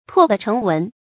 唾地成文 注音： ㄊㄨㄛˋ ㄉㄧˋ ㄔㄥˊ ㄨㄣˊ 讀音讀法： 意思解釋： 形容文思敏捷。